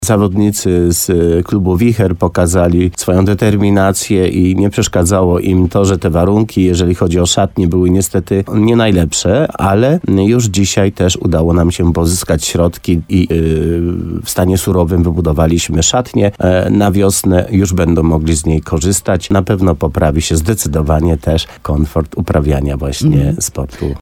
Jak powiedział radiu RDN Nowy Sącz wójt Leszek Skowron, nowe zaplecze wraz z sanitariatami było długo oczekiwane przez zawodników, którzy do tej pory musieli korzystać z blaszaka.